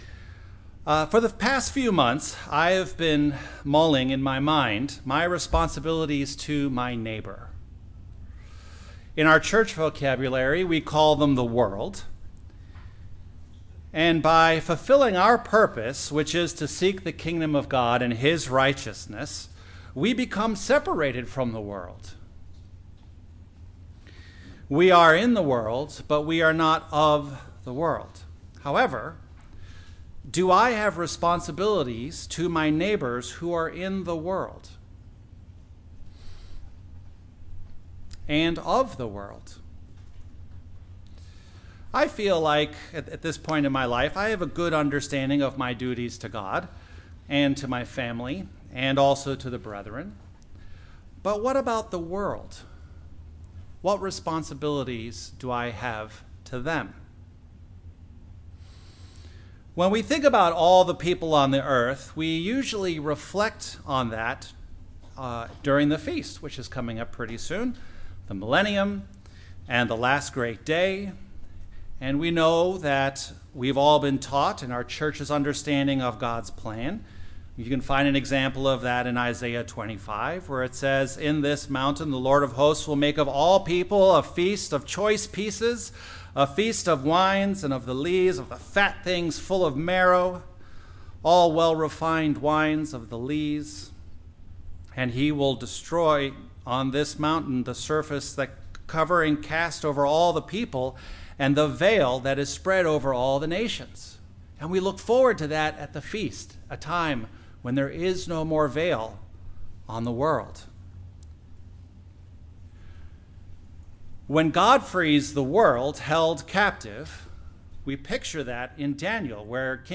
Sermons
Given in Beloit, WI